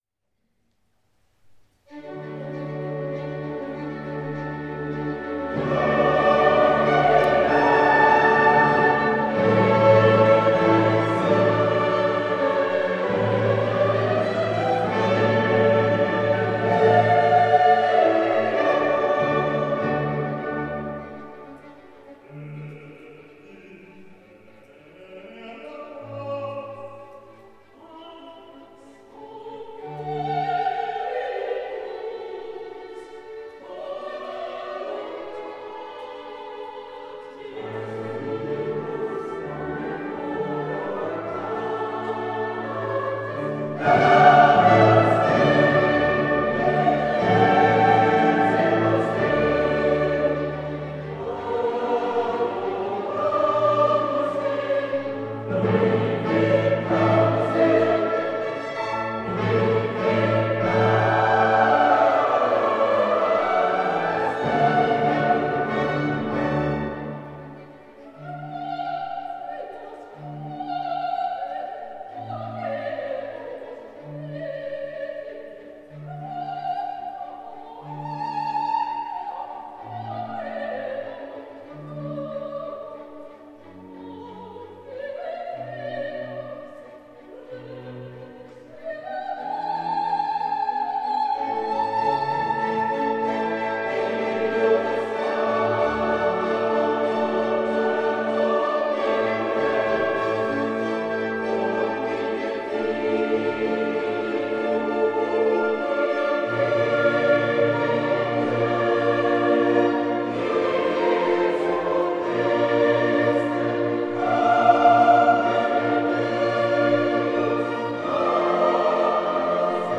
Kirchenchor St. Maria
(Ostern)
Carl Maria von Weber (1786 – 1826) Messe in G- Dur („Jubelmesse“) für Soli, Chor, Orchester und Orgel op. 76